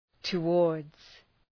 Προφορά
{tə’wɔ:rdz}